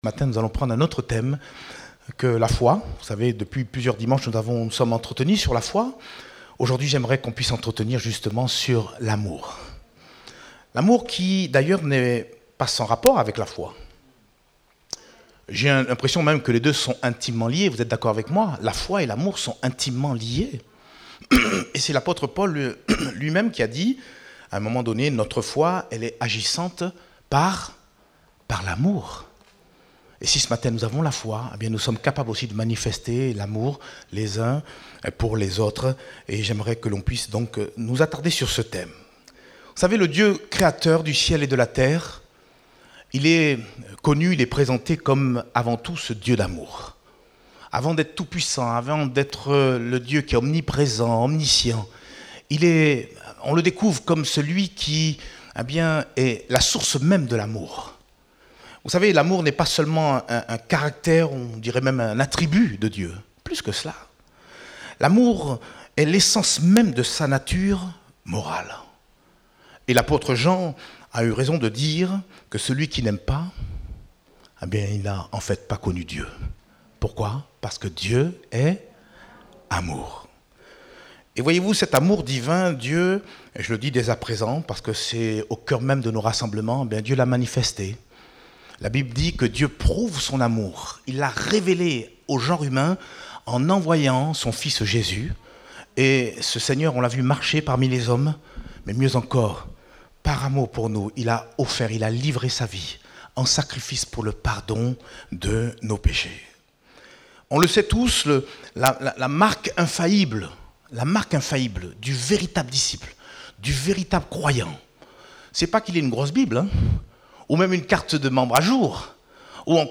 Date : 11 juillet 2021 (Culte Dominical)